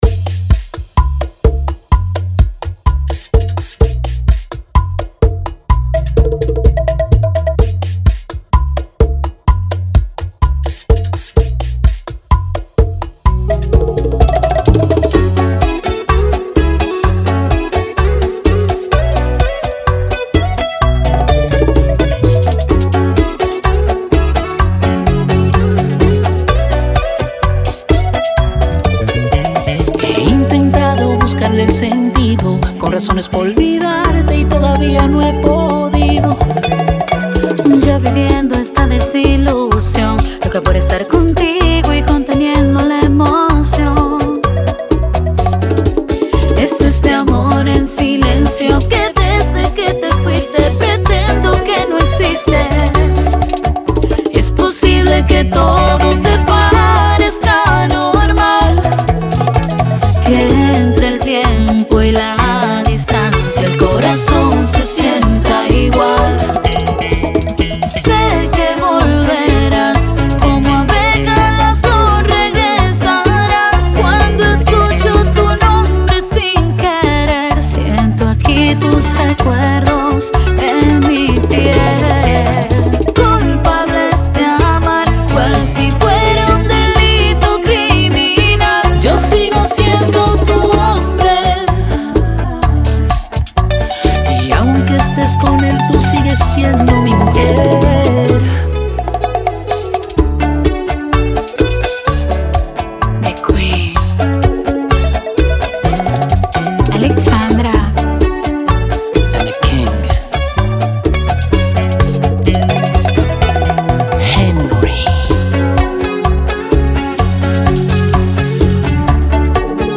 Genre: Bachata.